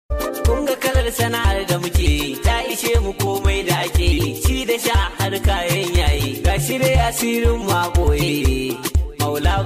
2.3M views trike sound effects free download